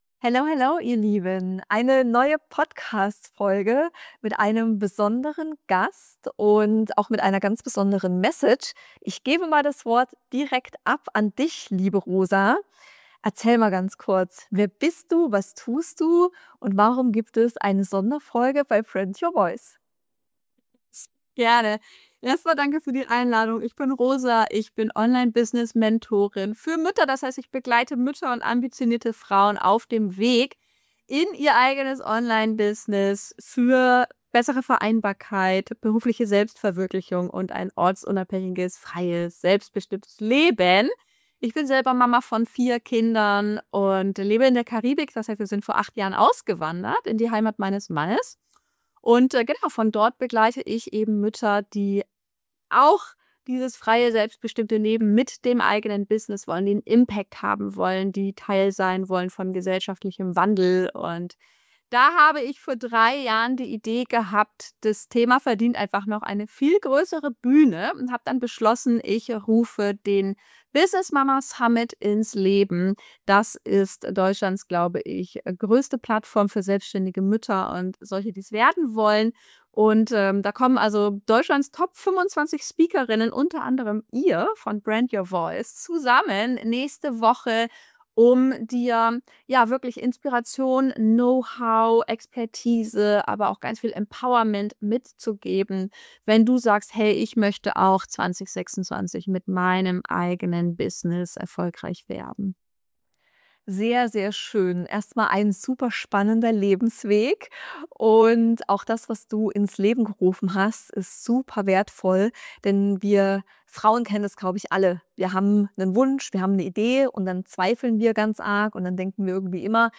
In dieser Podcastfolge hörst du ein empowerndes Interview